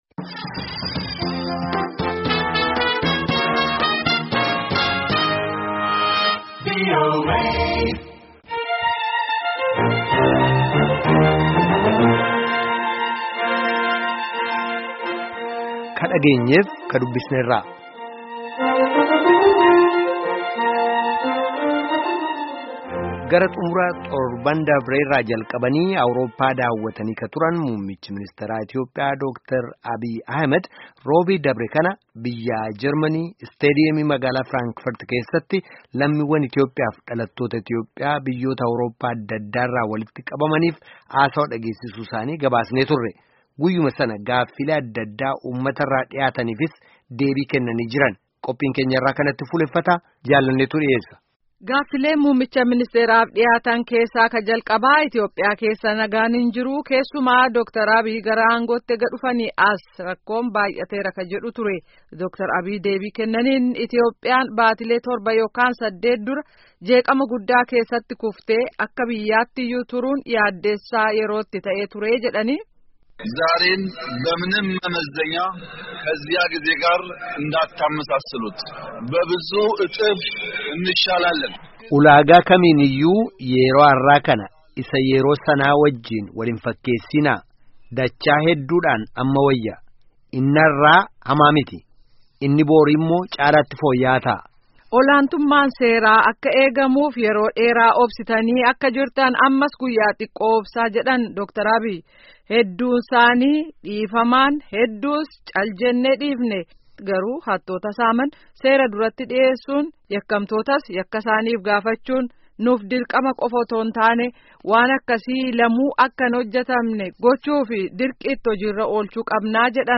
Gaaffii fi Deebii Dr. Abiyyi Jarmaniitti Uummata Waliin Taasisan
Gara xumura torban dabree irraa jalqabanii Awurooppaa daawwatanii ka turan – muummichi-ministaraa Itiyoophiyaa – Dr. Abiyyi Ahimed, Roobii dabre kana, biyya Jarmanii, Isteediyeemii magaalaa Fraankfert keessatti, lammiwwan Itiyoophiyaa fi dhalattoota Ityoophiyaa biyyoota Awurooppaa adda addaa...